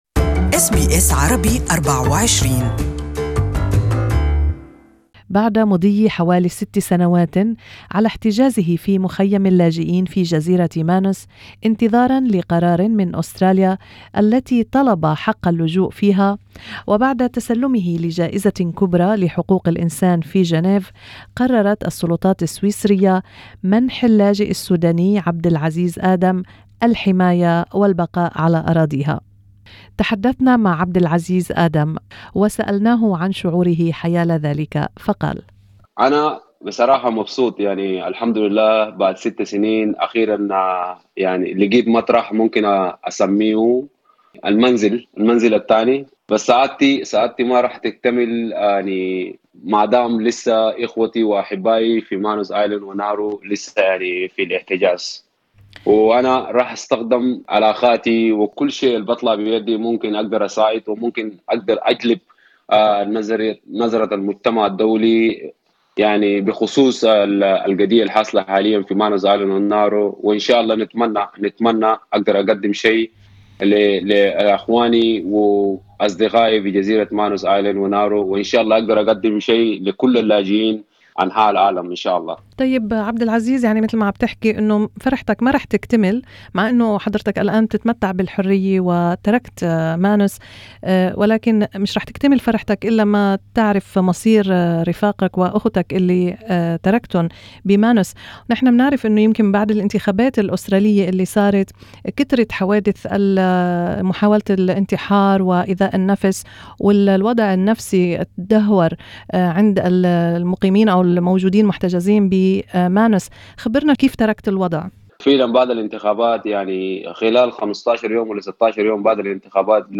استعموا الى اللقاء كاملا بالعربية تحت الشريط الصوتي في أعلى المقال.